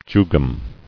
[ju·gum]